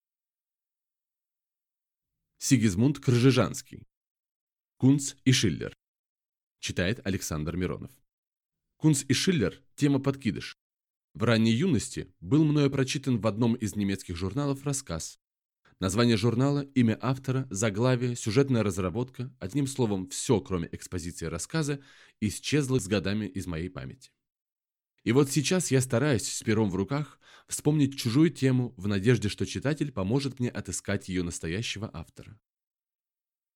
Аудиокнига Кунц и Шиллер | Библиотека аудиокниг